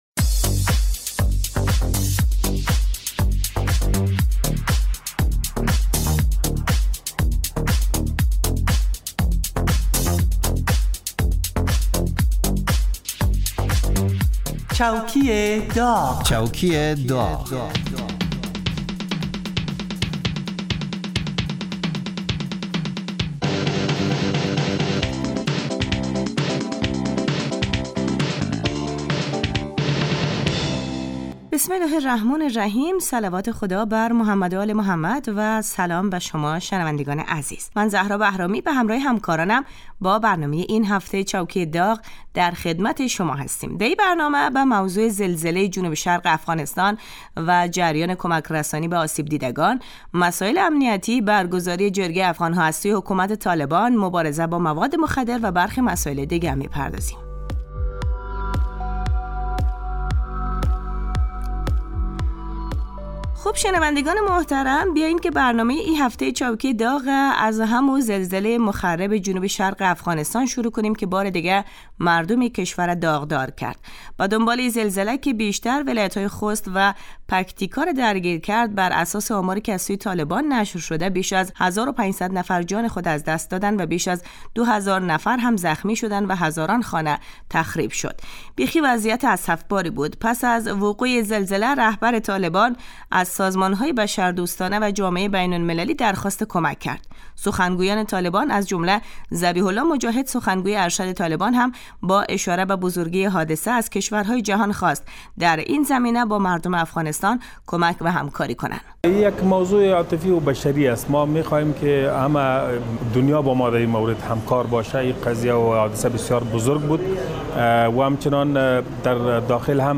برنامه چوکی داغ به مدت 30 دقیقه روز جمعه در ساعت 13:35 (به وقت افغانستان) پخش می شود. این برنامه با نیم نگاه طنز به مرور و بررسی اخبار و رویدادهای مهم مربوط به دولت حاکم در افغانستان می پردازد.